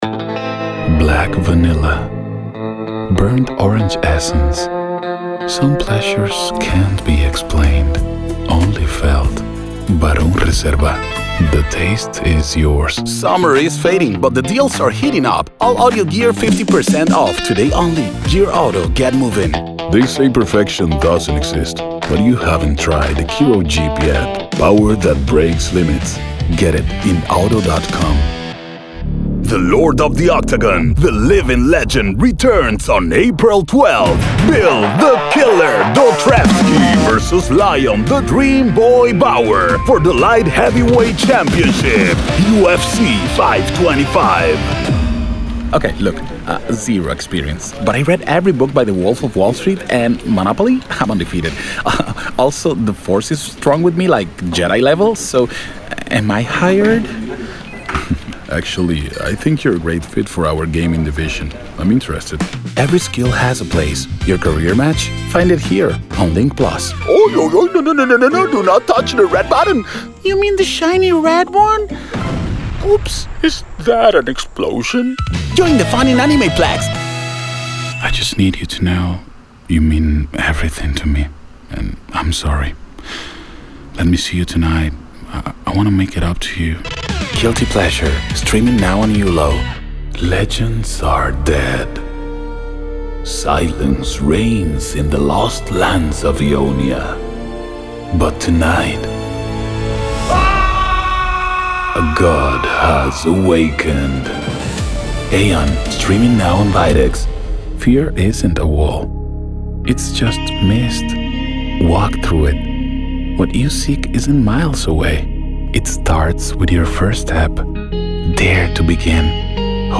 2026 English Voiceover Demo
English latino, español dominicano, español neutro.
Voice Over Demo.mp3